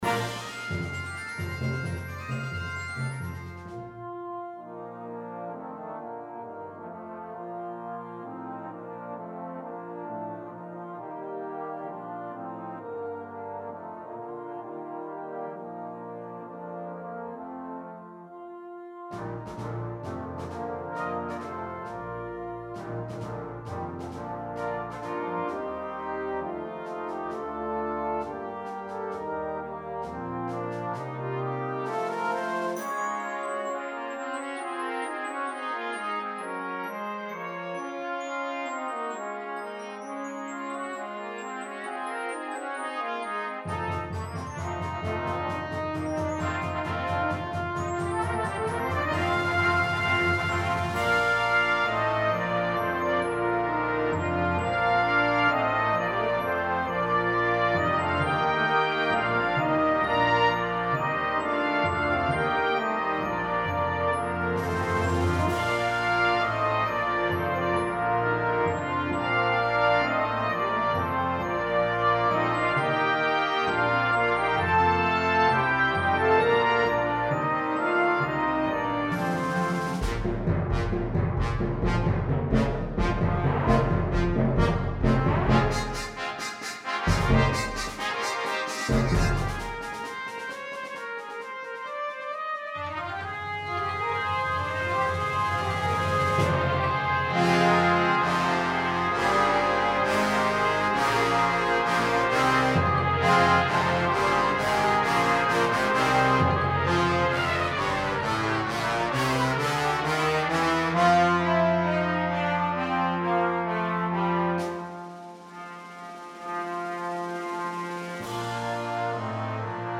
ist von einer mitreissenden Energie erfüllt
Besetzung: Brass Band